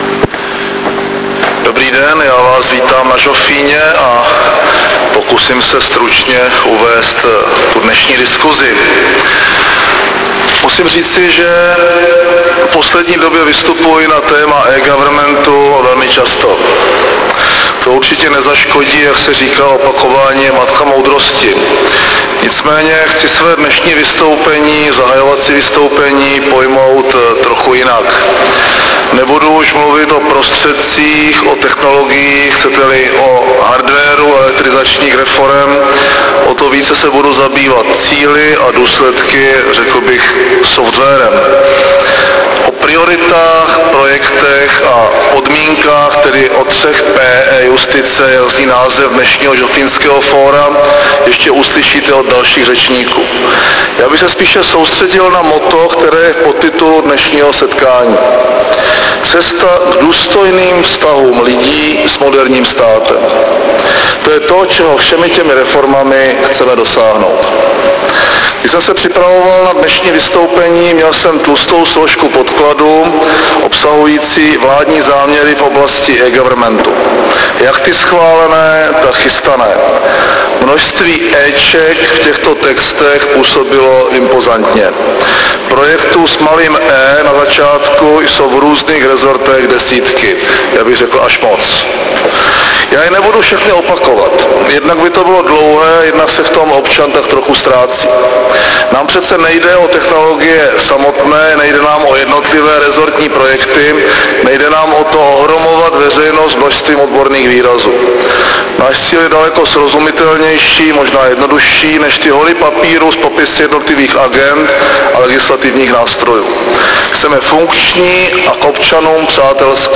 Projev premiéra Mirka Topolánka na 79. žofínském fóru 2008 -